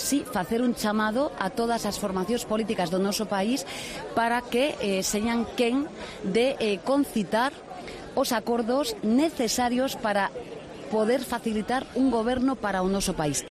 "Lo que es bueno para España y para Cataluña es tener una democracia sólida y robusta", ha dicho en declaraciones a los medios de comunicación desde su Galicia natal.